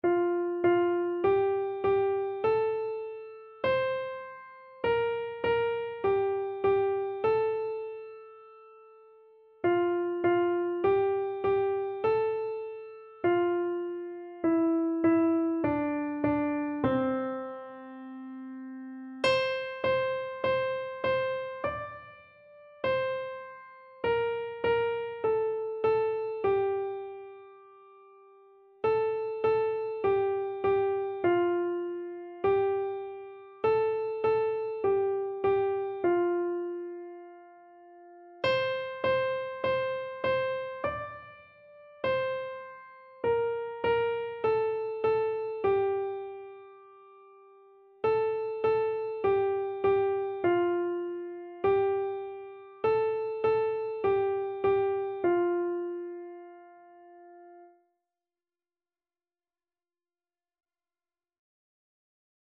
Christian Christian Keyboard Sheet Music Like a River Glorious
Free Sheet music for Keyboard (Melody and Chords)
F major (Sounding Pitch) (View more F major Music for Keyboard )
4/4 (View more 4/4 Music)
Keyboard  (View more Easy Keyboard Music)
Classical (View more Classical Keyboard Music)